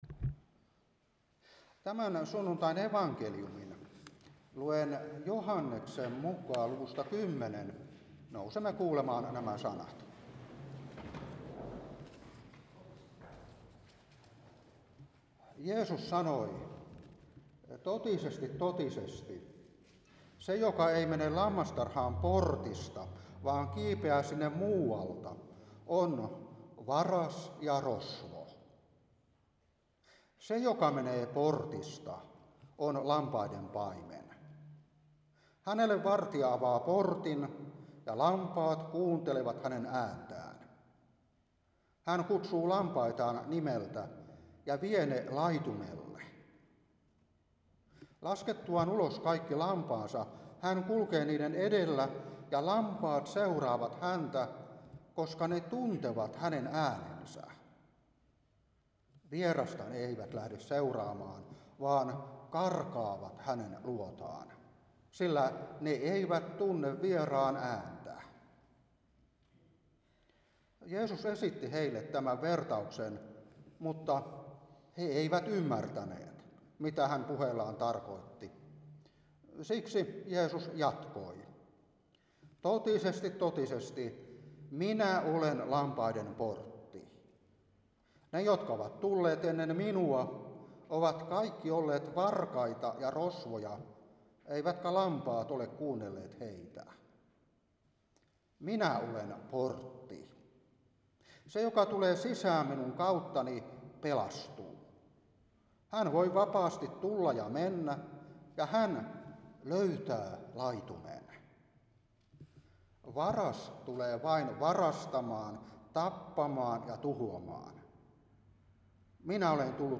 Saarna 2024-4.